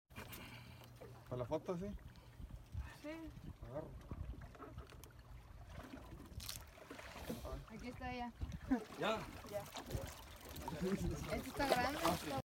I felt the pain of that fish when I heard the crunch